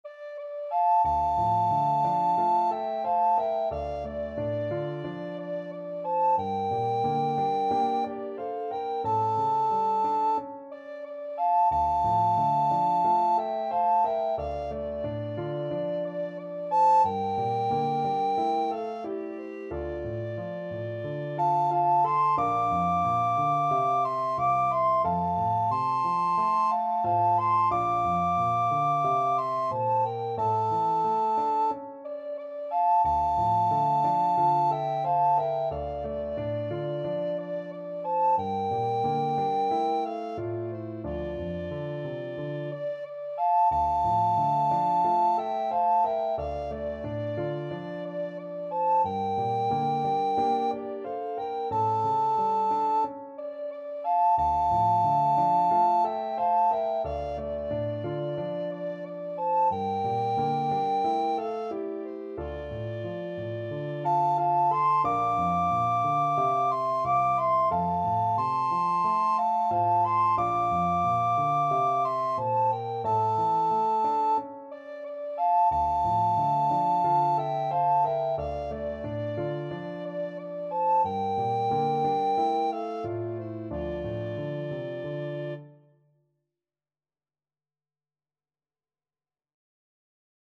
Free Sheet music for Recorder Duet
Soprano RecorderAlto RecorderPianoTenor Recorder
D minor (Sounding Pitch) (View more D minor Music for Recorder Duet )
4/4 (View more 4/4 Music)
Andante = c. 90